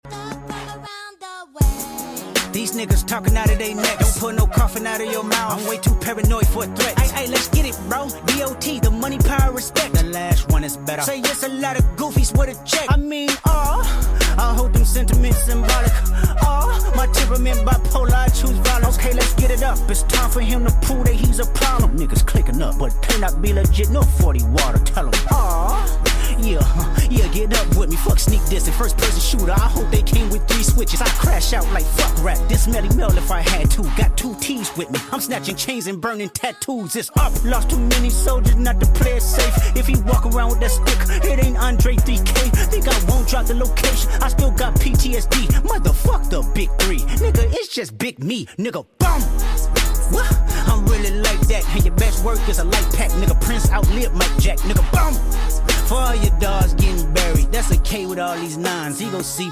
Nike LeBron 21 Mp3 Sound Effect LeBest Colorway?? Nike LeBron 21 “Multi-Color” Traction/Squeak Test Did Nike just make the best-looking LeBron 21 colorway? Featuring a mismatching translucent outsole in different vibrant colors, this pair has excellent traction performance on a relatively dusty court with a consistent squeak.